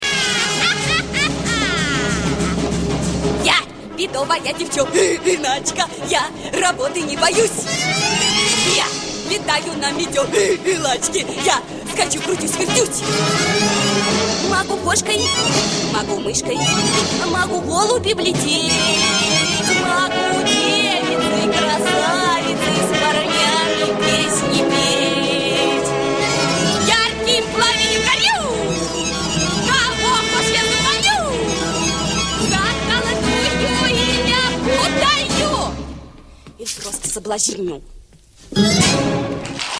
единственная песня в исполнении героини это вот эта.